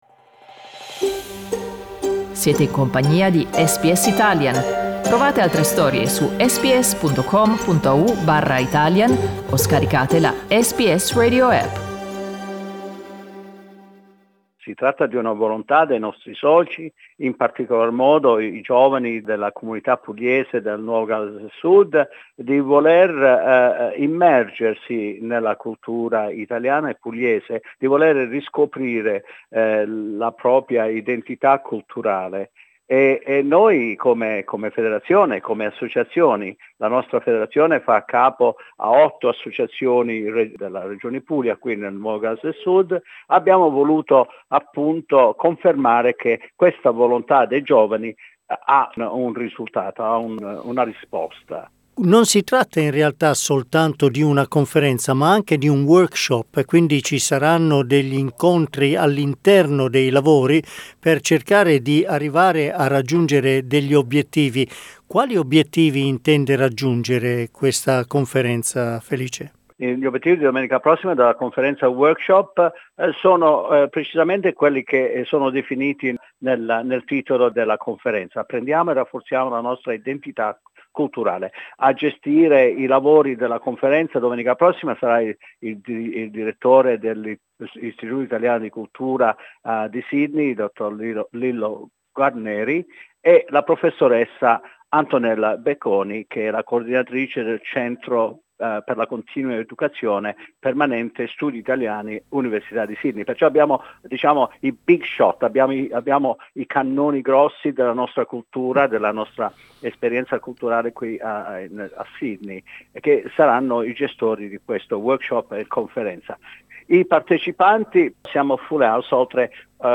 Ascolta l'intervista: LISTEN TO Conferenza sull'identità culturale degli italiani del NSW SBS Italian 07:48 Italian Le persone in Australia devono stare ad almeno 1,5 metri di distanza dagli altri.